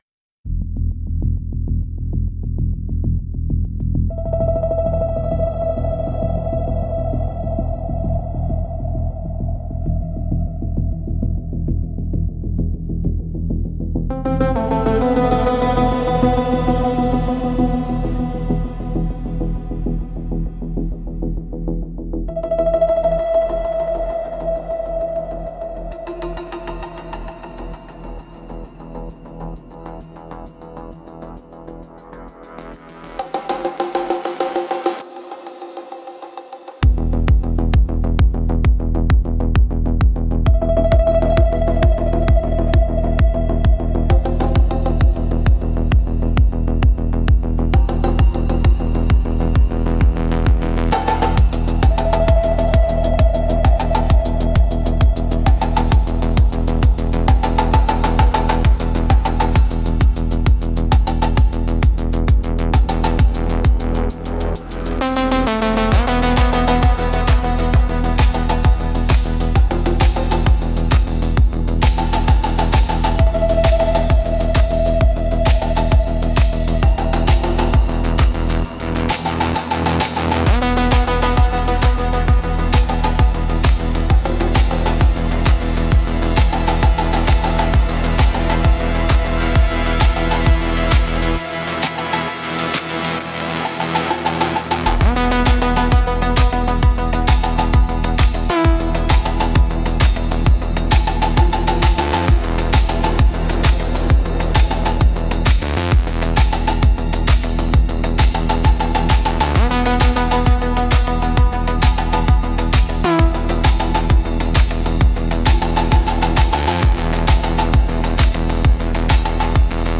Instrumentals - Throat Singing the Mainframe